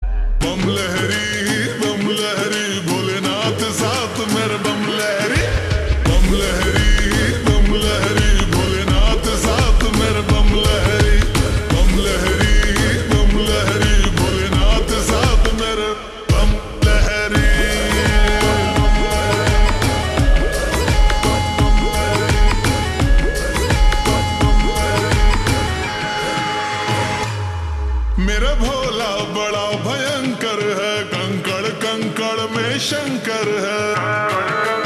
Hindi Songs
( Slowed + Reverb)